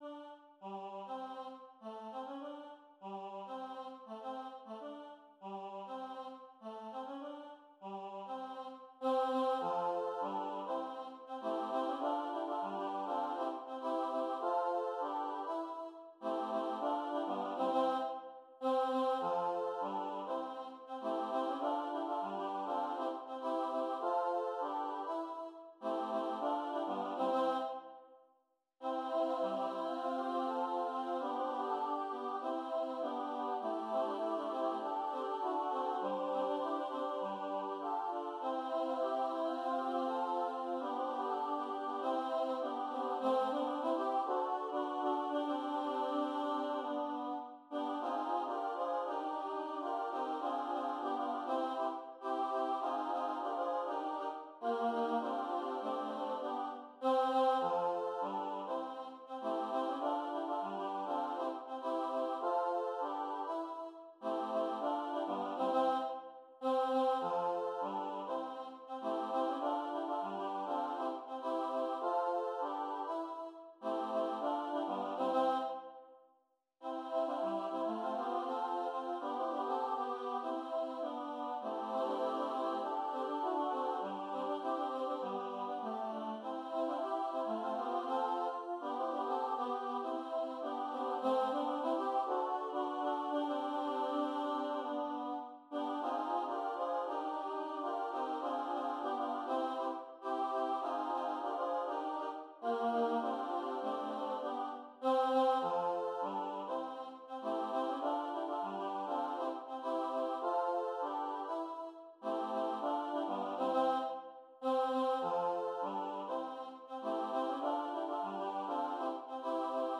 kan synges både a cappella og med akkompagnement